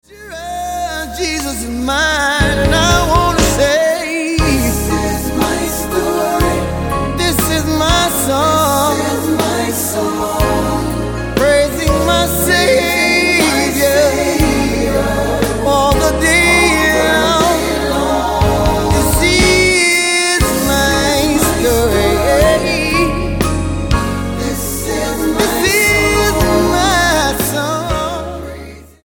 STYLE: R&B
old-style production